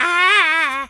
monkey_chatter_angry_09.wav